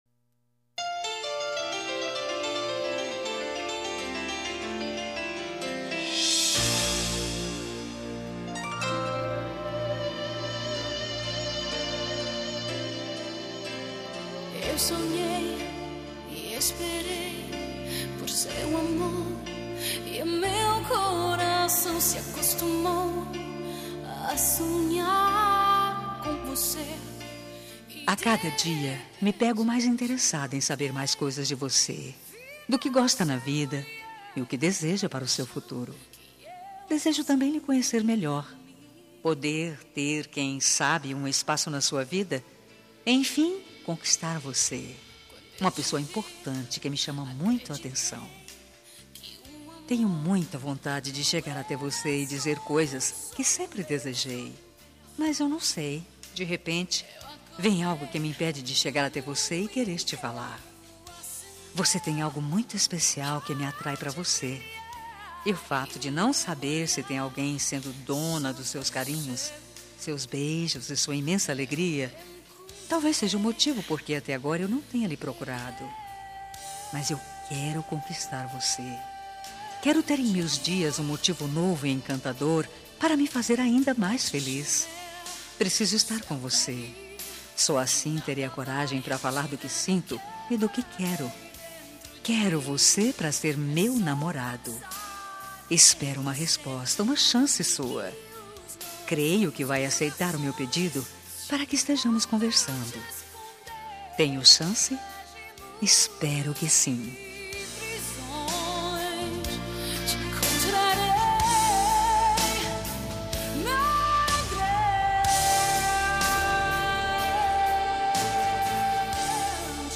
Telemensagem de Conquista – Voz Feminina – Cód: 140115